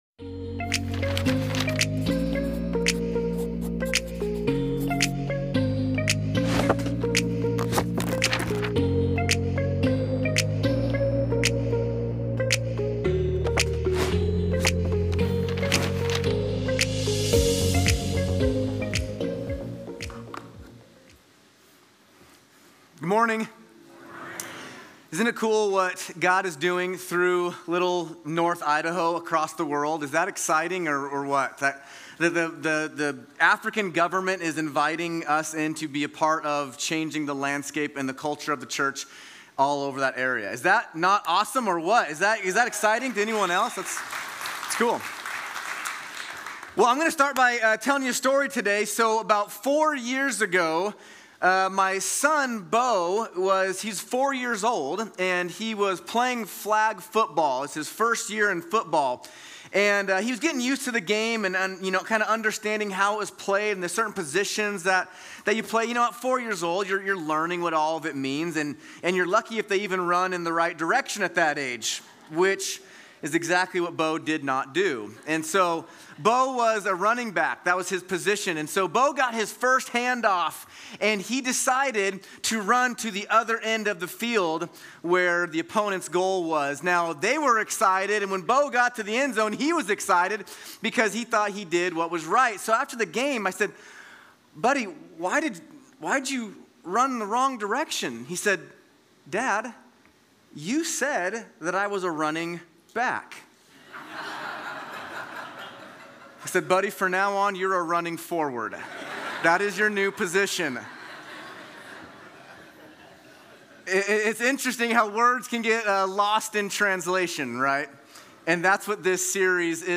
Hayden Campus
Sermon